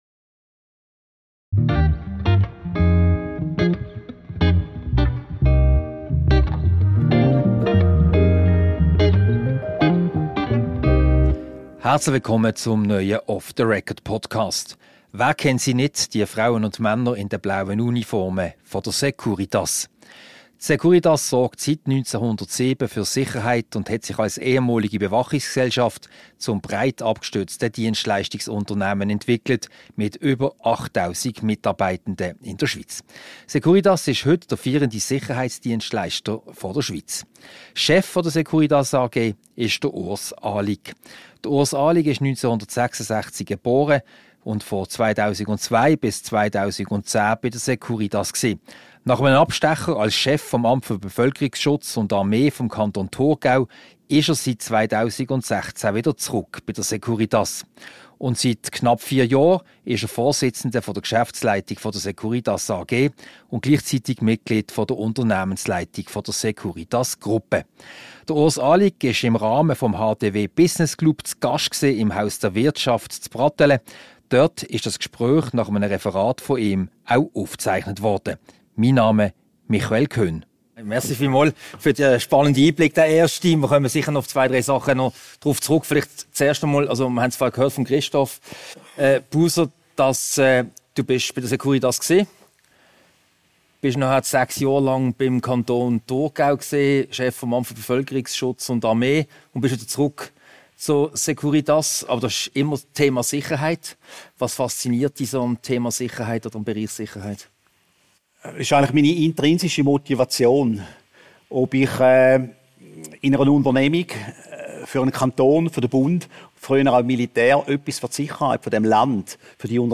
Ein Gespräch über Sicherheit, Digitalisierung im Sicherheitsbereich und den FC Liverpool. Aufgezeichnet am HDW Business Club Lunch vom 29. Februar 2024.